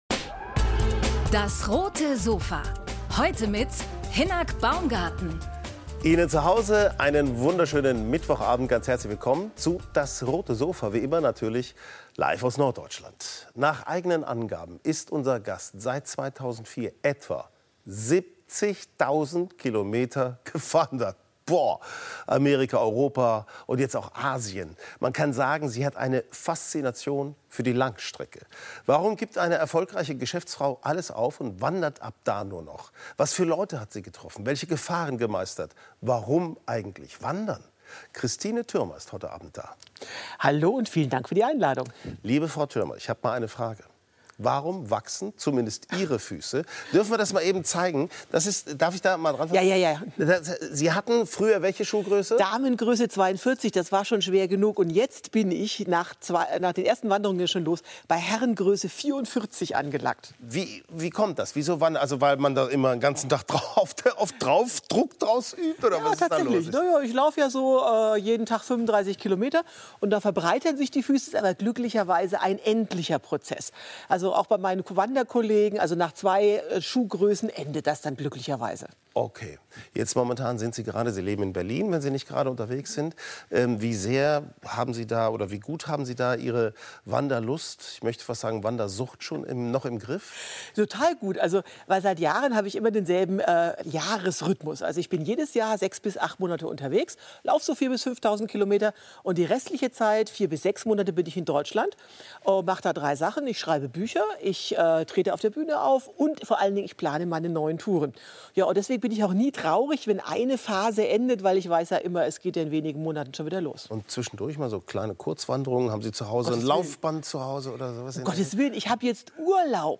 im Talk über ihre neuesten Reisen ~ DAS! - täglich ein Interview Podcast